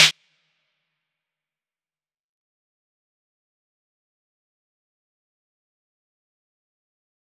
DMV3_Snare 3.wav